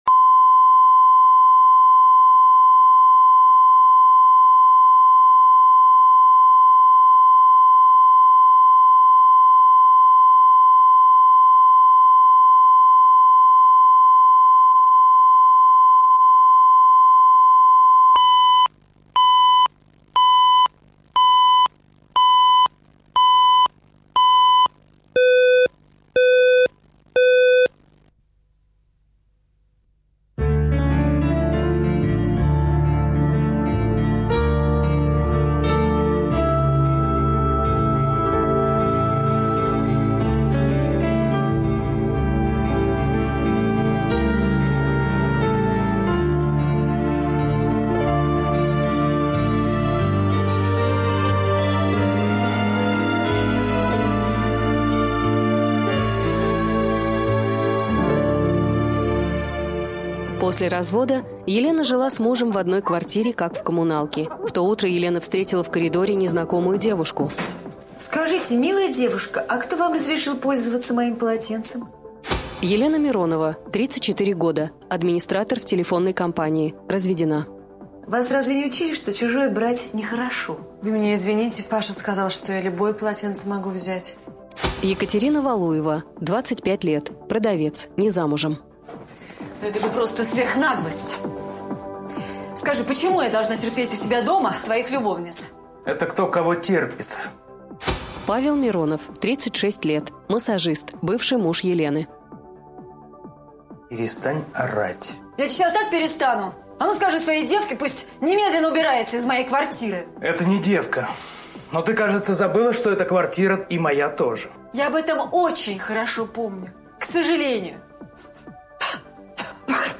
Аудиокнига Половинки | Библиотека аудиокниг